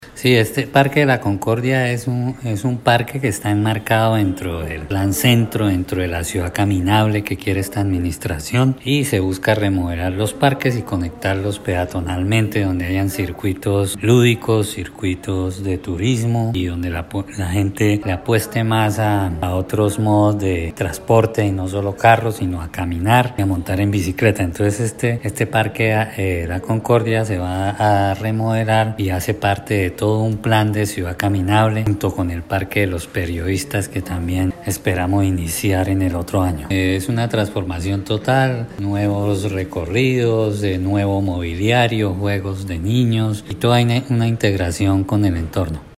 Descargue audio: Iván José Vargas, secretario de Infraestructura